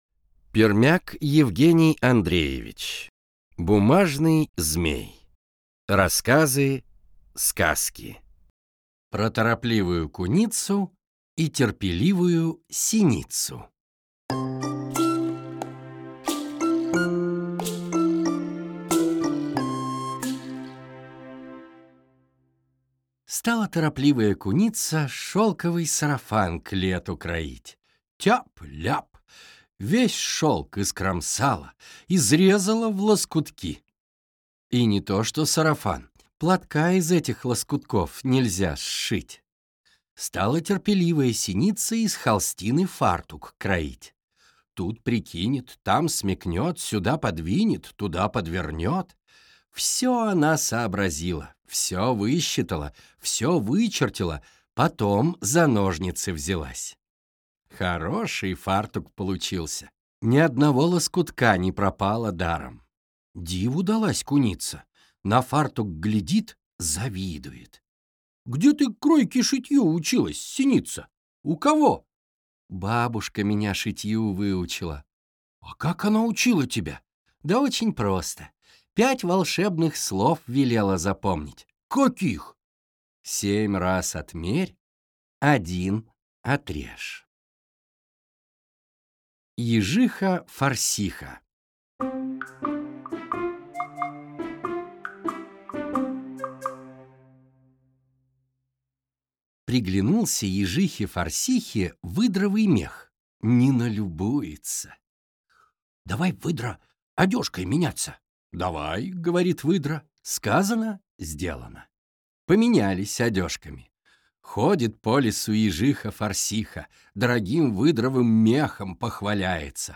Аудиокнига Бумажный змей | Библиотека аудиокниг
Прослушать и бесплатно скачать фрагмент аудиокниги